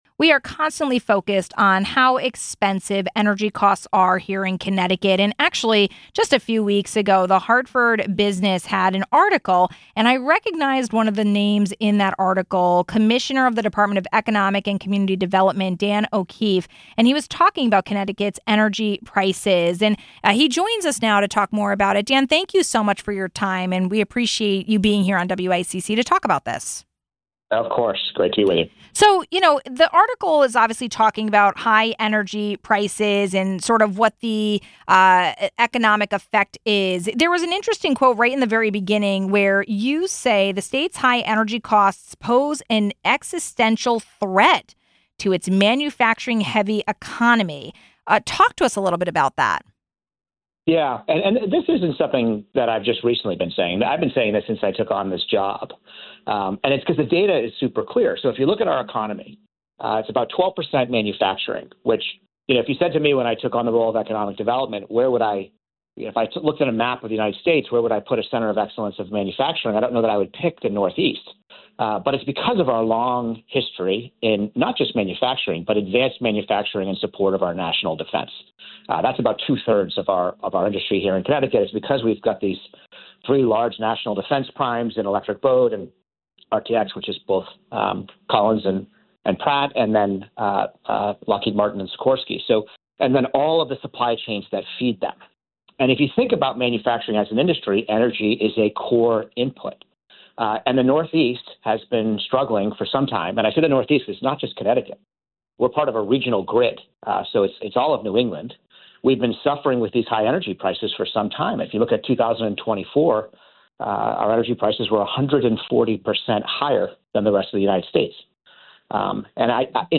We focus a lot on high energy costs impacting homeowners; but what about its economic impact from a business perspective? We spoke with Commissioner Dan O’Keefe, of the Department of Economic and Community Development.